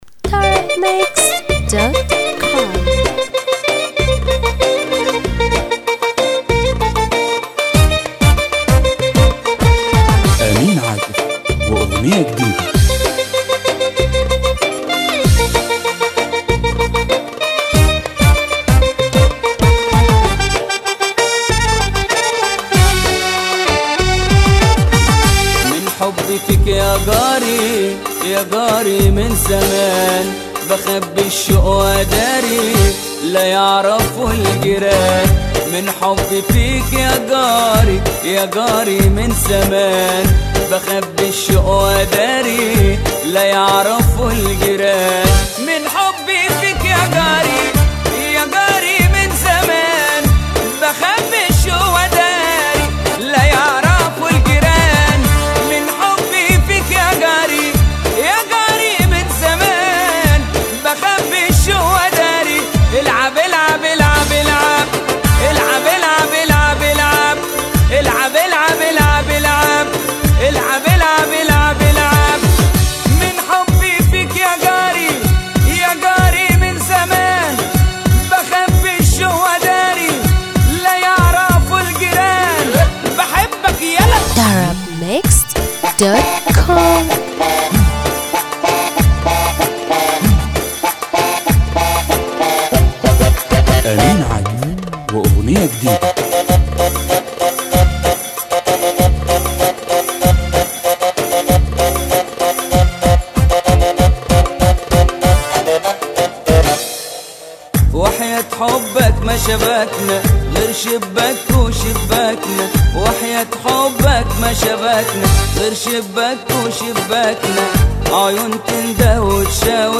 اغانى شعبى